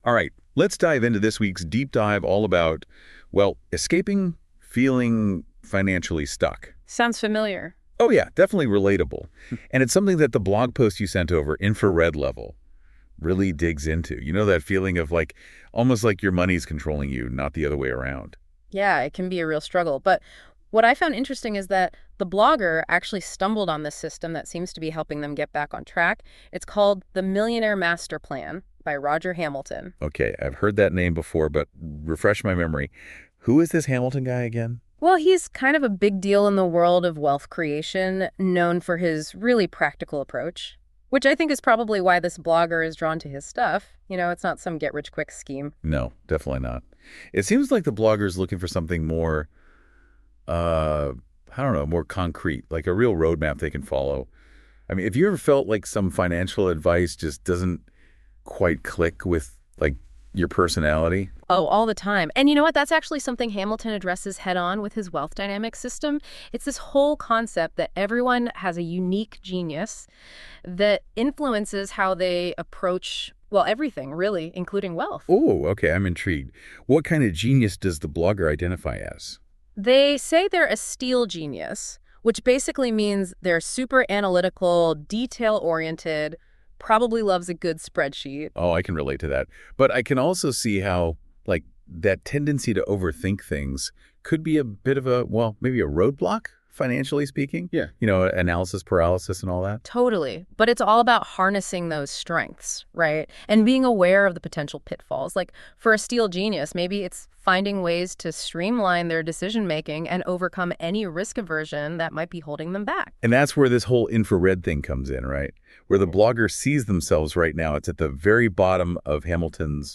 The two AI-hosts talk about my blogpost about the first three chapters of "Millionaire Master Plan" - a book which I am currently reading and want to take the journey myself.